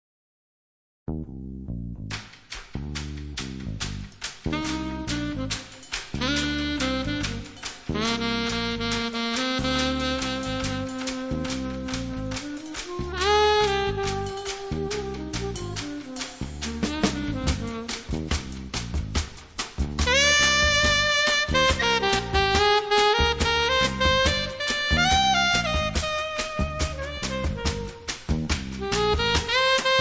atupan drums
berimbau
wea flute
cowbells, cabasa
• registrazione sonora di musica